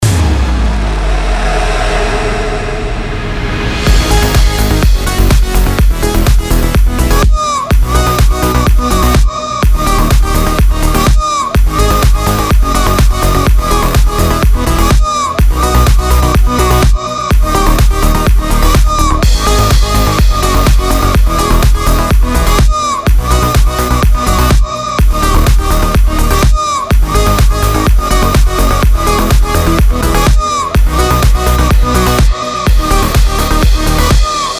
Слуханите плиз дроп.
А то уши уже в трубочку свернулись от этих жужжалок ))